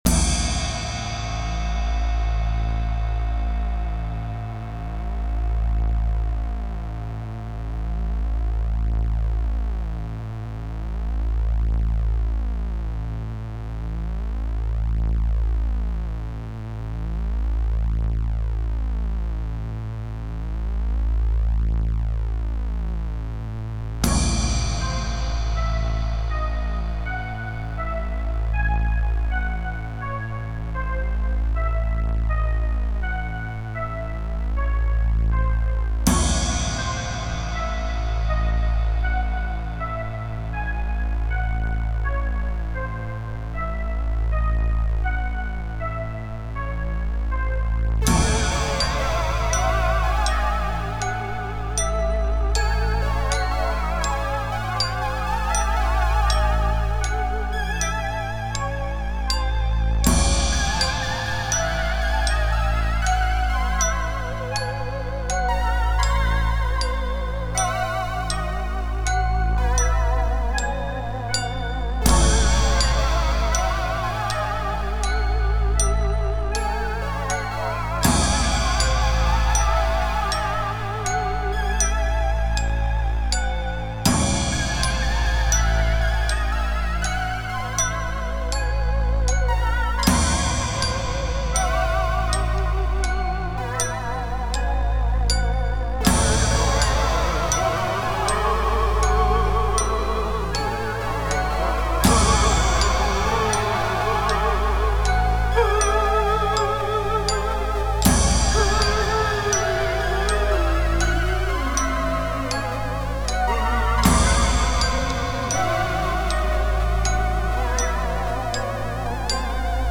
so far it's me on guitar, bass, keys, trumpet, sax, vocals
we are a zeuhl band